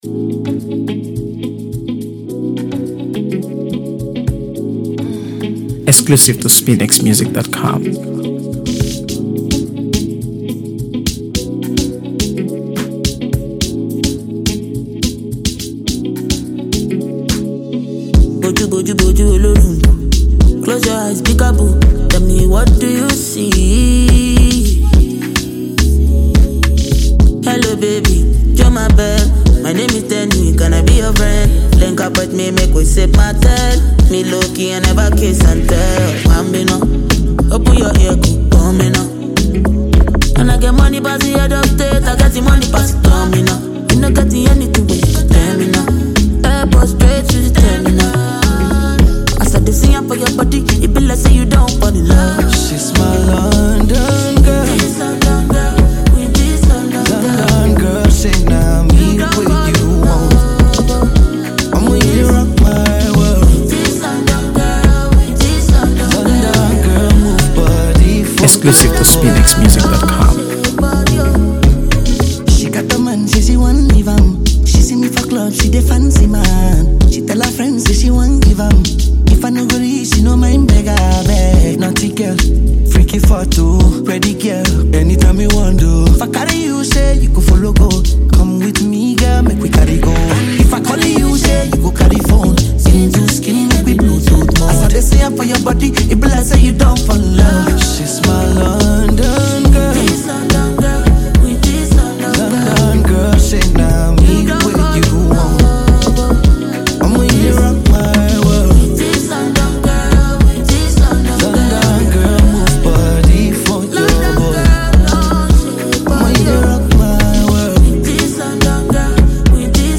is an energetic yet soulful song